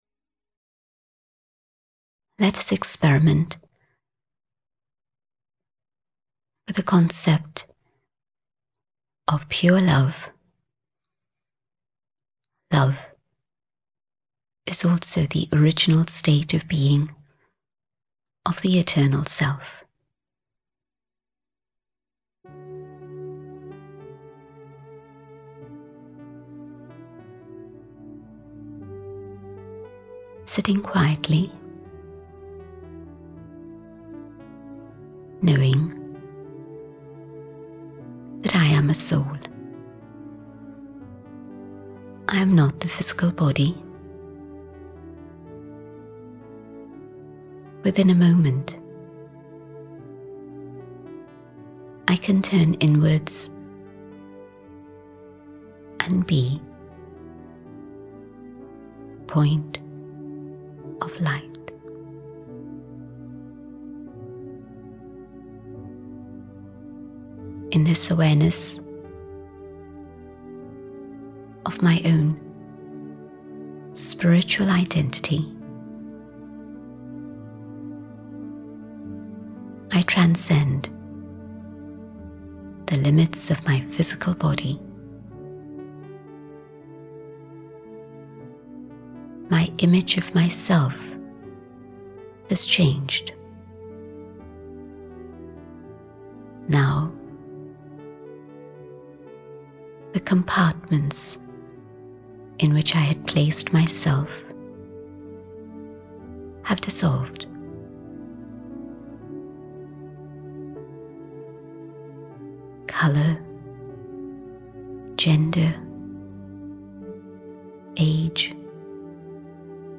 Morning Meditation - RAJA YOGA CENTER MUSCAT OMAN